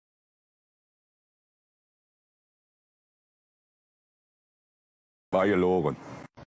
minecraft / sounds / mob / villager / no1.ogg